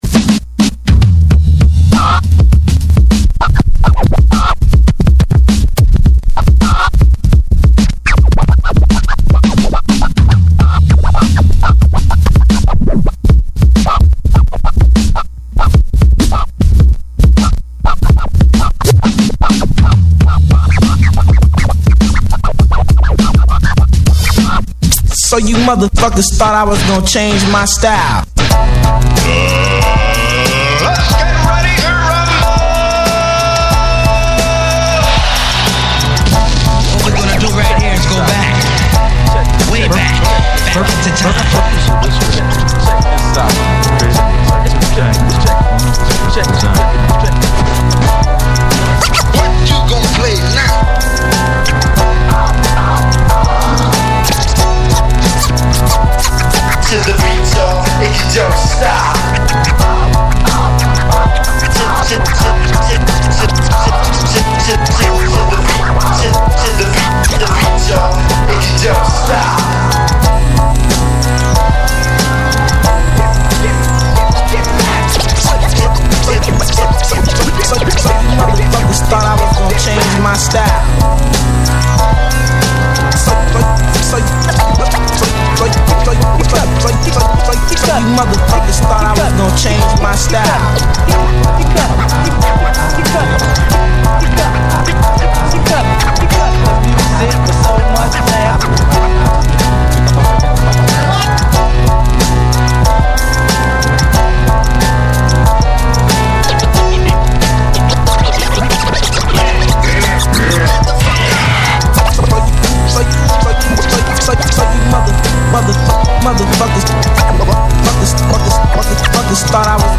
Old School Release Turntables and Scratches